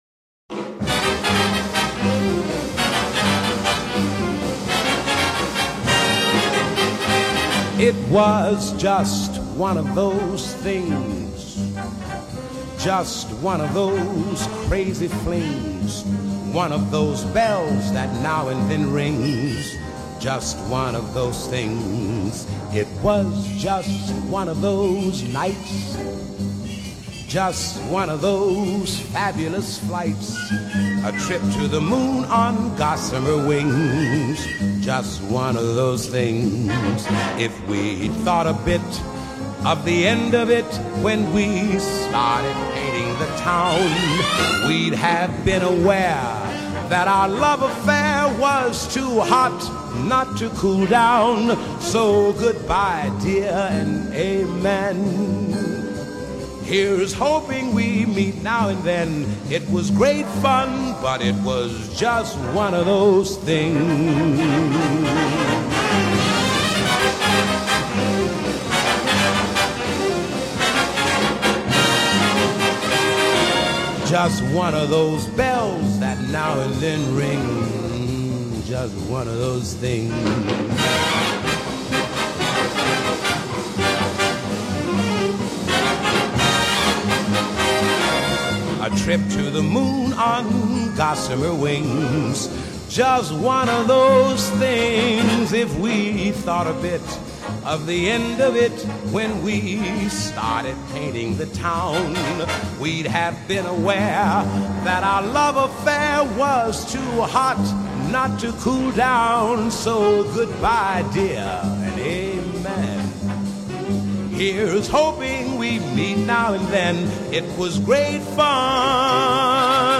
Jazz, Vocal Jazz